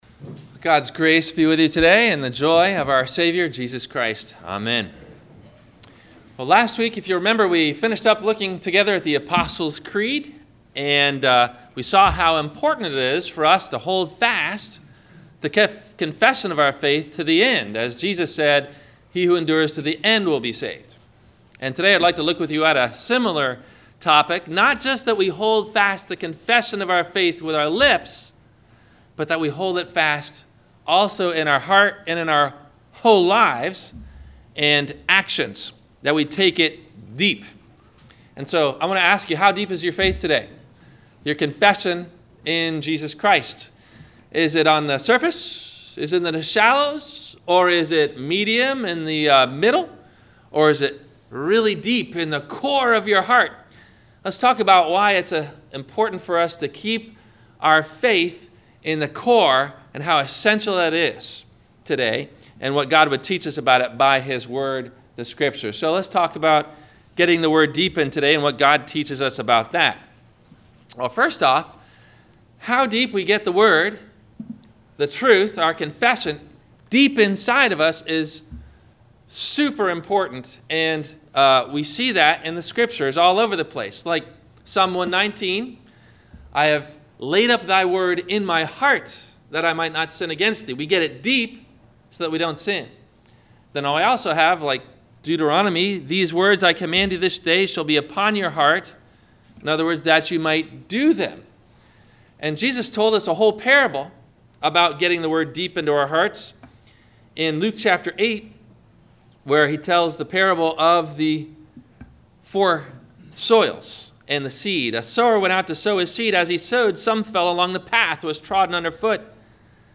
Are You a Hero or a Hypocrit? - Sermon - July 09 2017 - Christ Lutheran Cape Canaveral